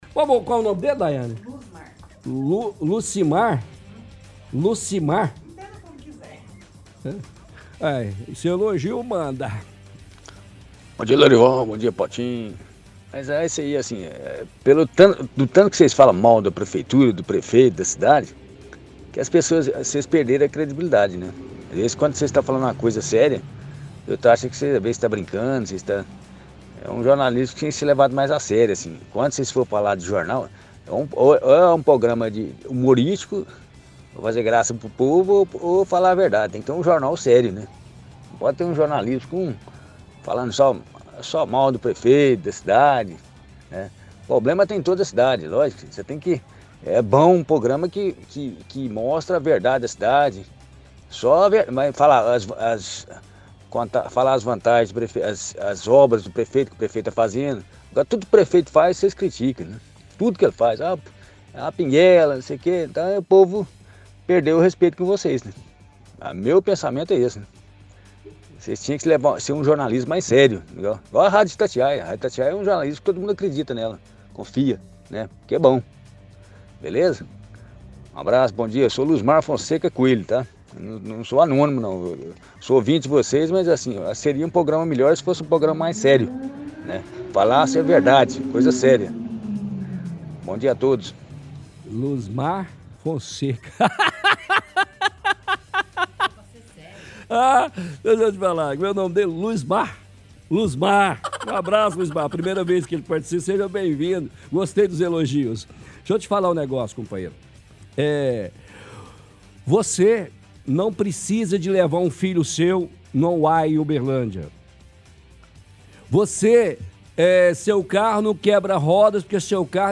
– Piadas utilizando áudios do prefeito.